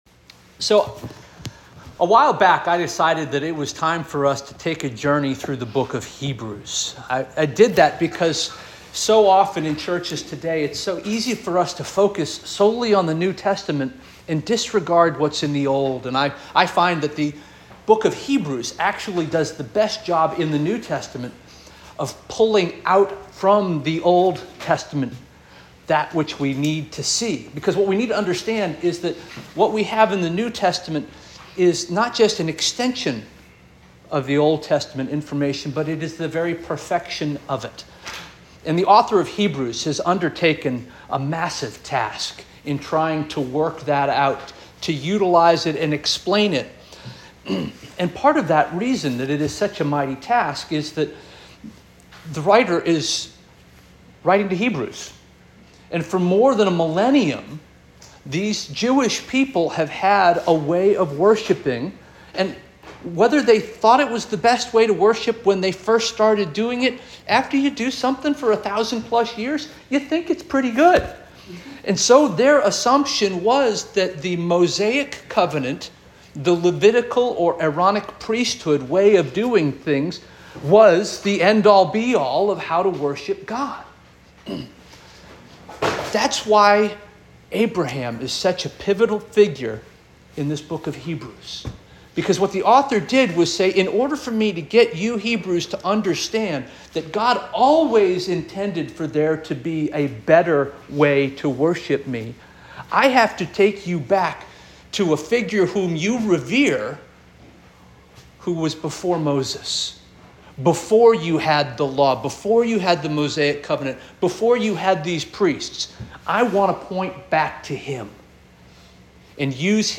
May 19 2024 Sermon